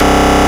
Index of /90_sSampleCDs/Roland - Rhythm Section/BS _Synth Bass 1/BS _Wave Bass